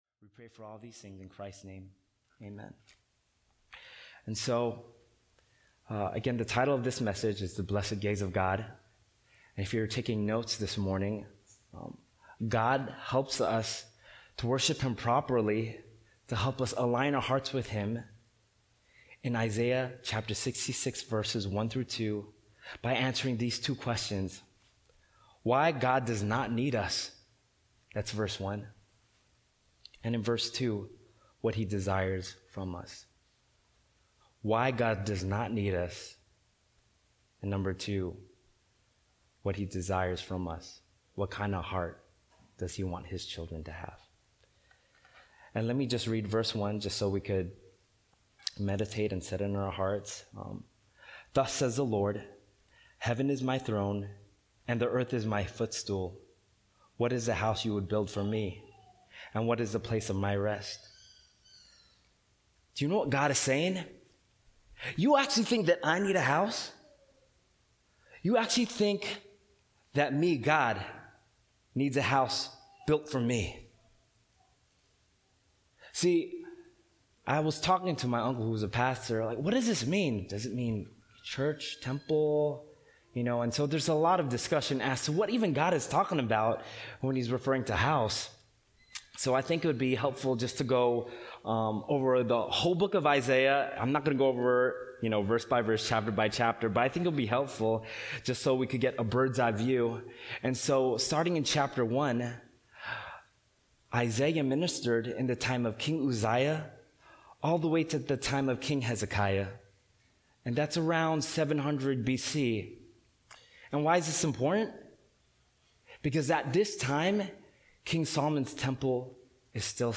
Sunday Service)Bible Text